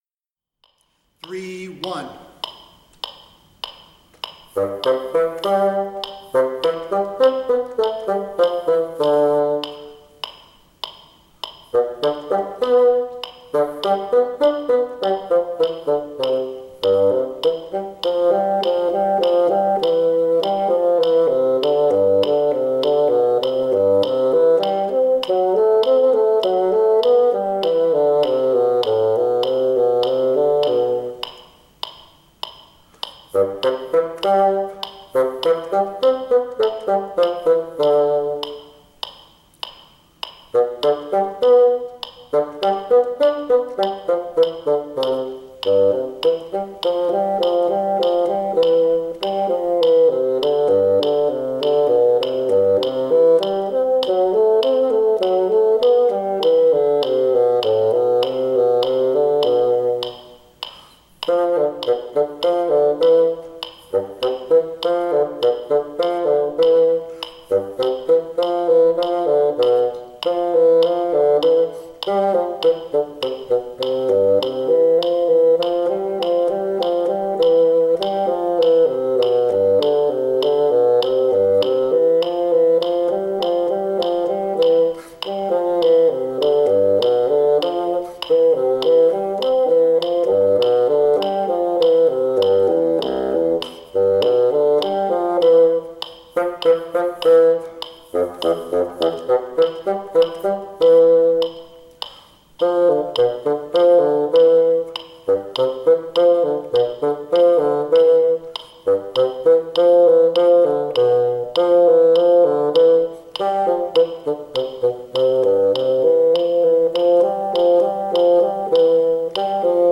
P. 17, Duet in C Major, Tempo 100, Bassoon 2